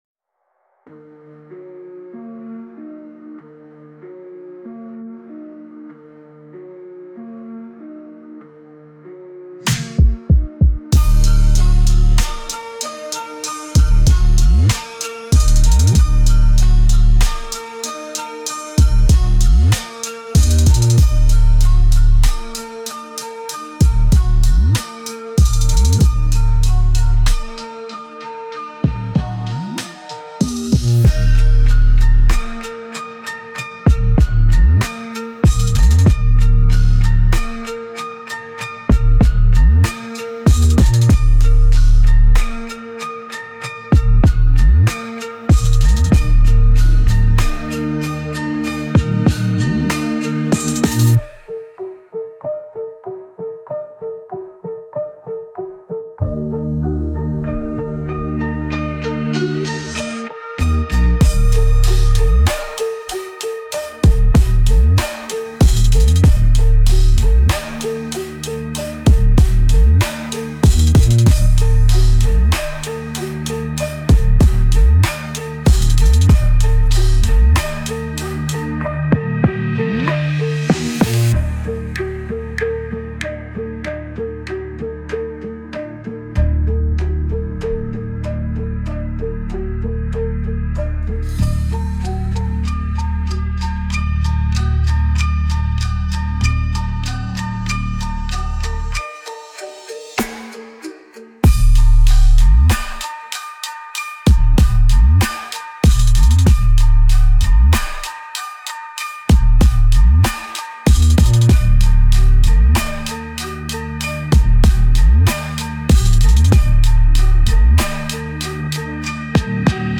Instrumental - RLMradio Dot XYZ - 4 mins.mp3